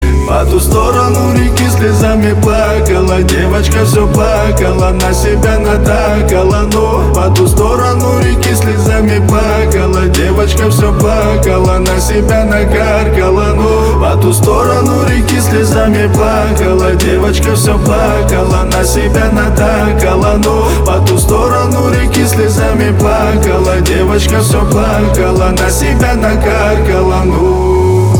• Качество: 320, Stereo
поп
мужской вокал
грустные
русский рэп